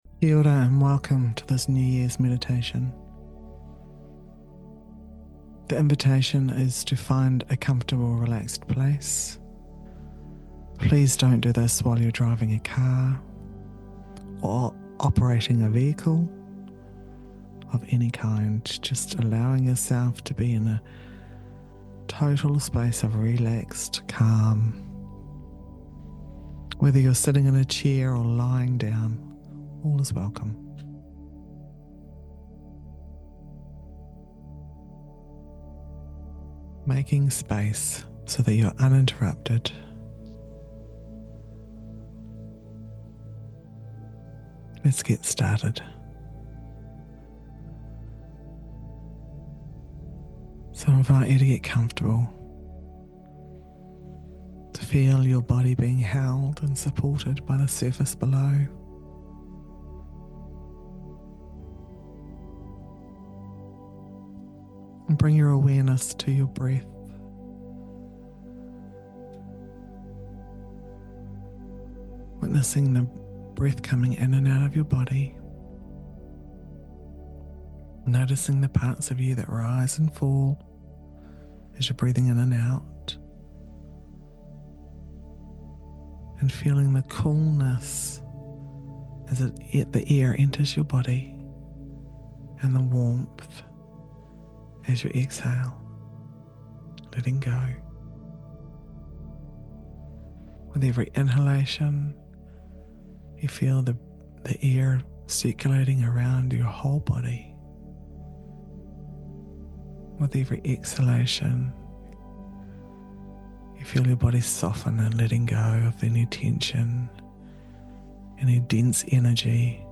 Sign up to receive a free audio meditation, designed to help you set powerful intentions for the year ahead.